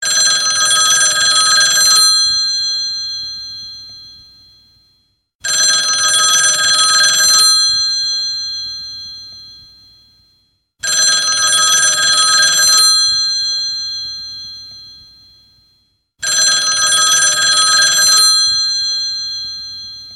Nhạc Chuông Báo Thức Để Bàn